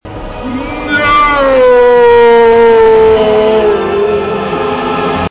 из фильмов
голосовые